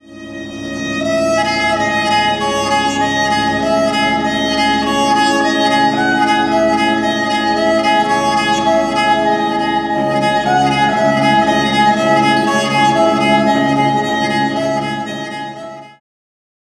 Electronic Organ
Kamanche